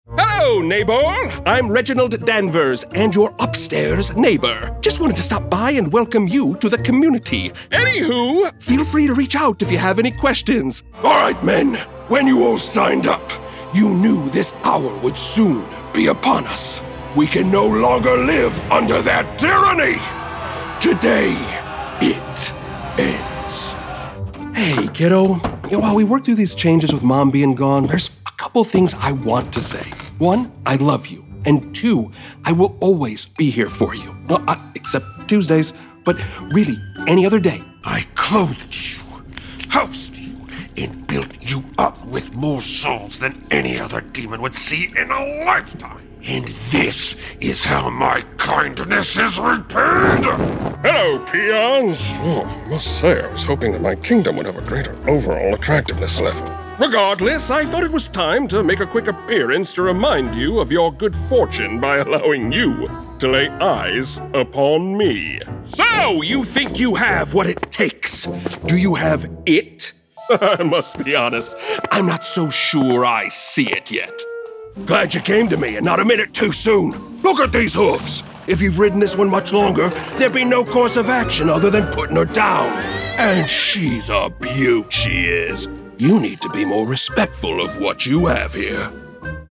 Adult, Mature Adult
Has Own Studio
british rp | character
cajun | character
midwestern us | character
midwestern us | natural
new york | character
southern us | character
standard us | natural
texan us | character
trans atlantic | character
ANIMATION 🎬